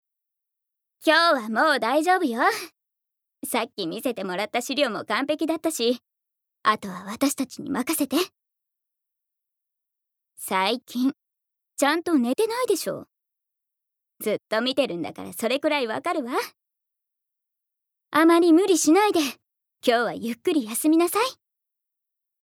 ボイスサンプル
セリフ６